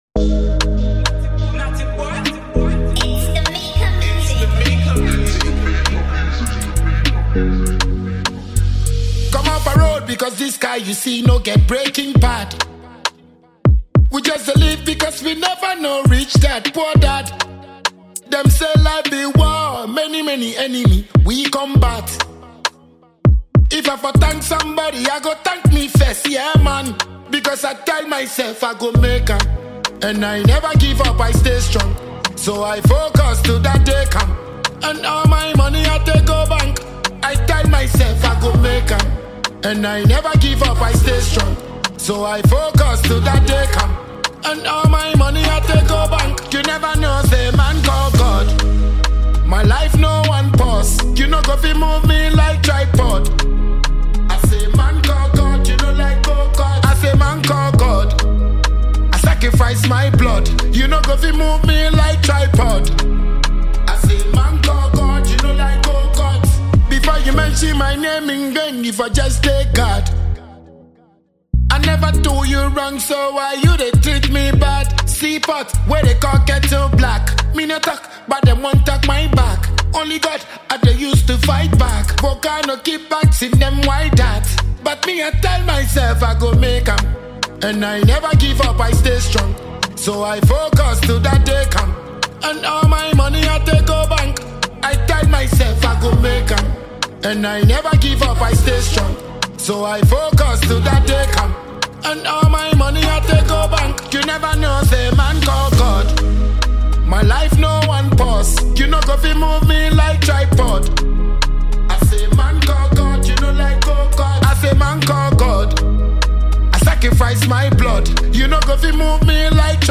a Ghanaian dancehall musician.